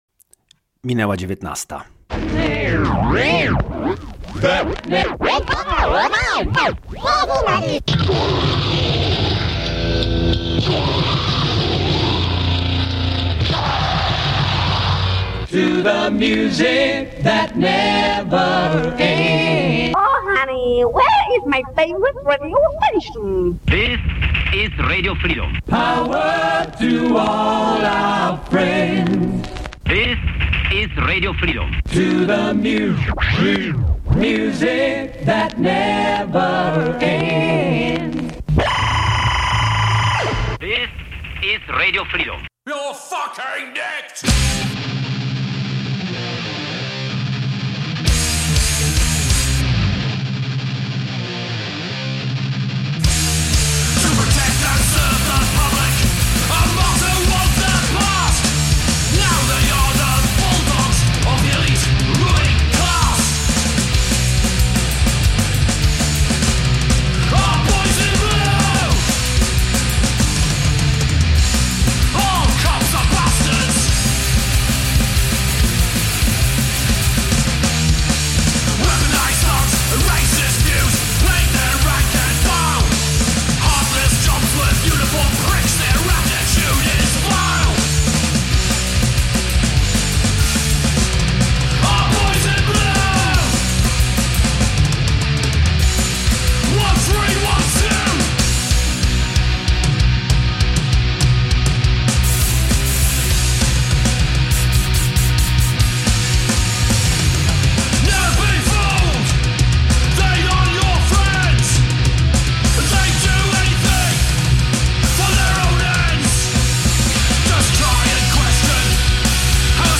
No i … jazz.